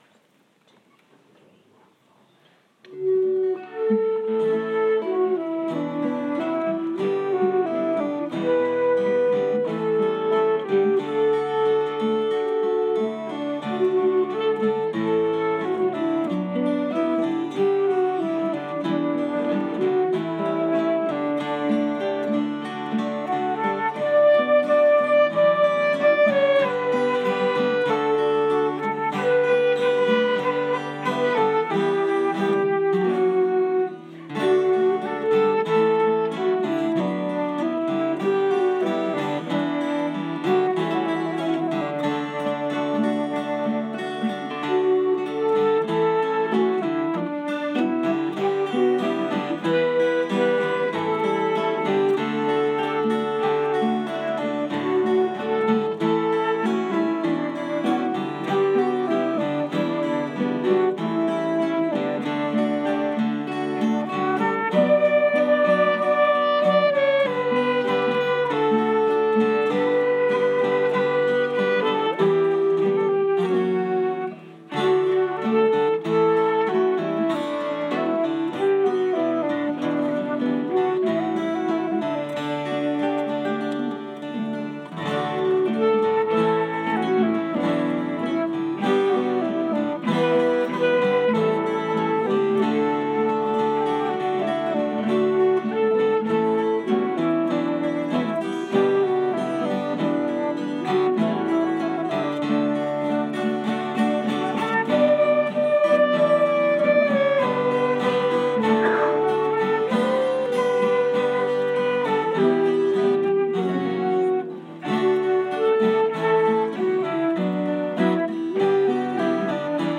sunday instrumental